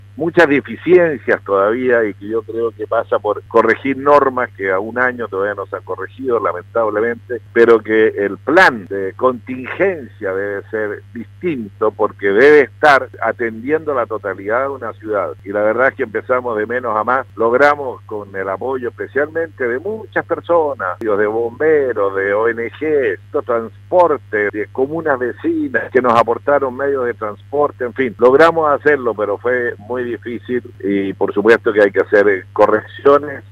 Por lo anterior, el Intendente de la región de Los Lagos, Harry Jürgensen en conversación con Radio Sago analizó y reflexionó sobre lo sucedido. En dicho contexto dijo que si tuviera la posibilidad cambiaría todo el plan de emergencia que se aplicó.